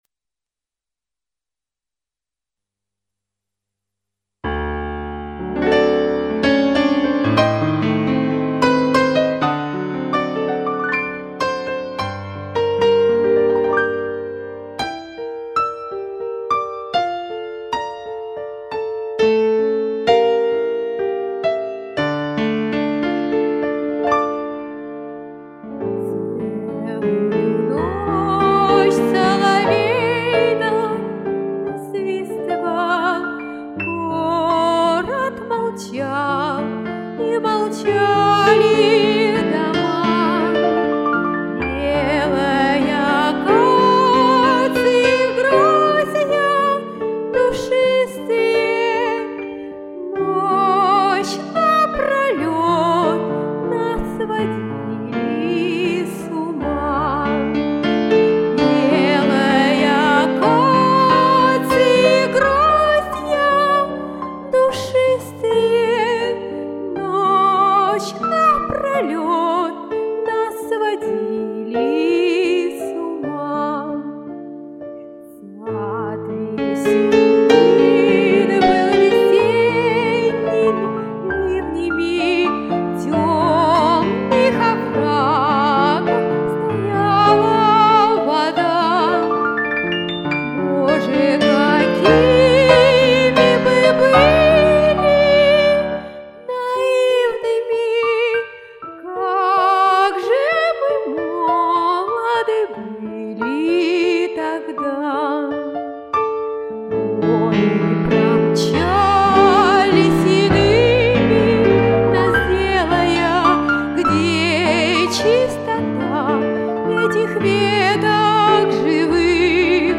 Ну а мужской голос только добавил краски.... 3:2